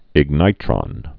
(ĭg-nītrŏn, ĭgnĭ-)